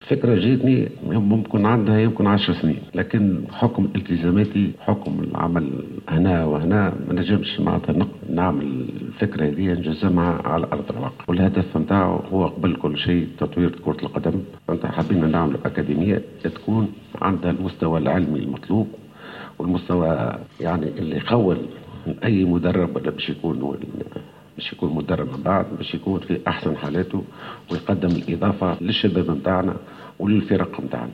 عقد اليوم المدرب فوزي البنزرتي ندوة صحفية بمدينة المنستير للإعلان عن إفتتاح أكاديمية دولية لتكوين مدربي كرة القدم وتعد الأولى من نوعها في إفريقيا وفي العالم العربي.